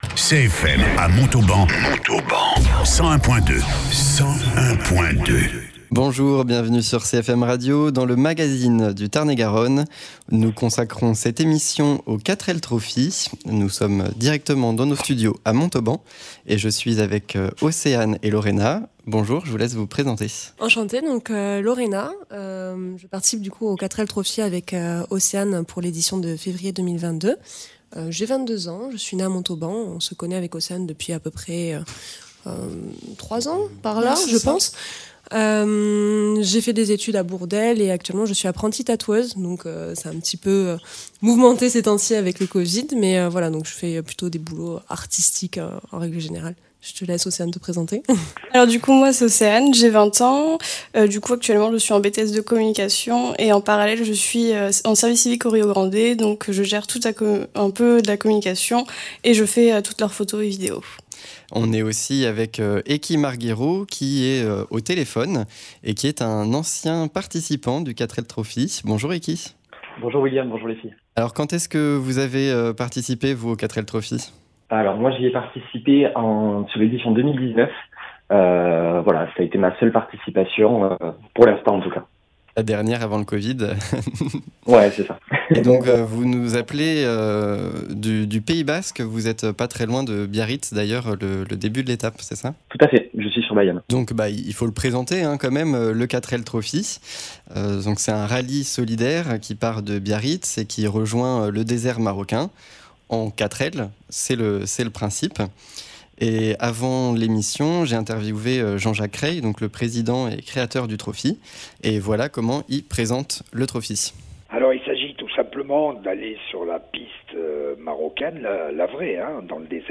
Deux montalbanaises ont comme projet de le faire en 2022 et ont commencé à réunir le budget nécessaire, elle sont invitées en studio. Un ancien participant témoigne également au téléphone.
Interviews